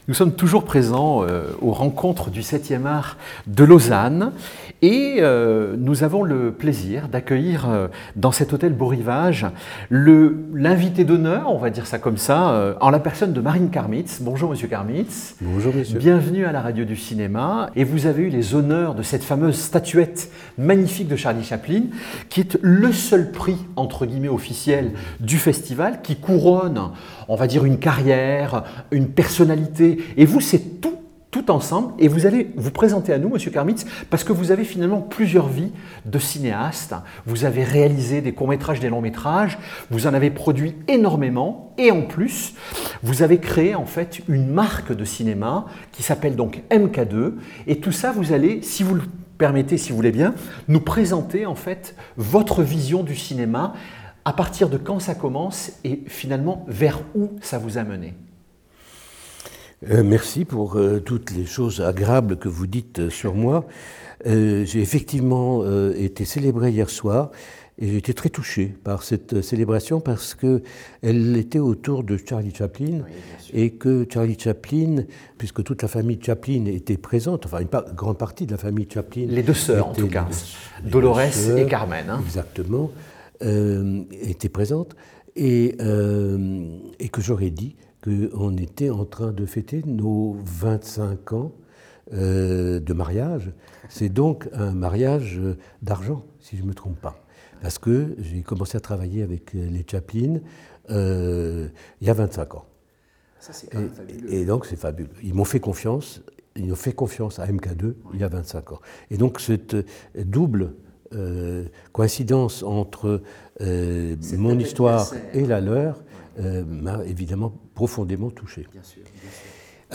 Rencontres 7e Art Lausanne 2026 • salle, production, transmission